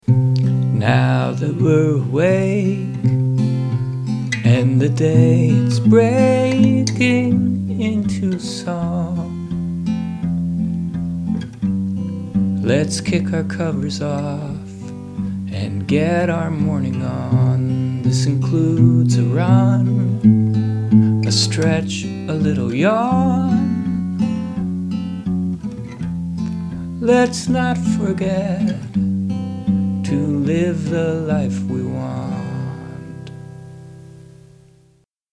C, F, E, Am, C/G, F, C/G, G, C, G/B, Am, C/G, D/F#, F, G, C
verse verse